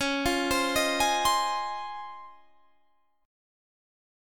Listen to C#M9 strummed